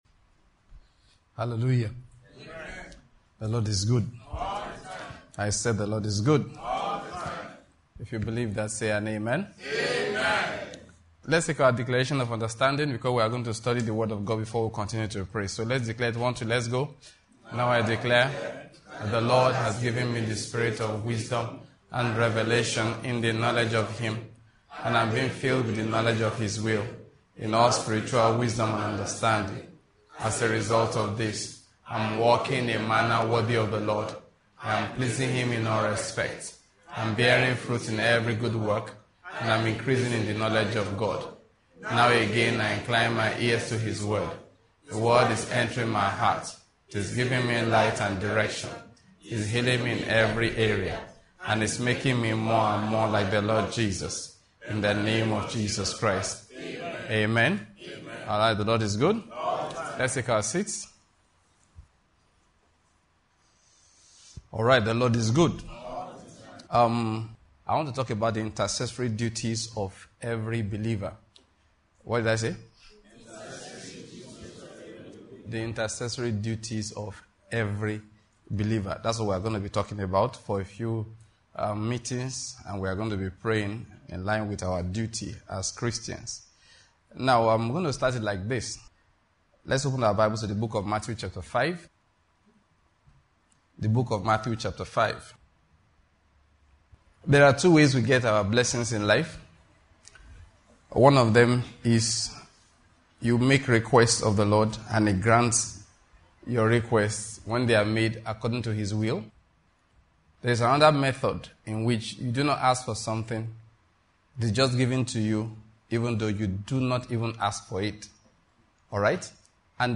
Intercessory duty of believers: biblical prayer and intercession for nations and communities in this Kingdom-Word Ministries teaching series.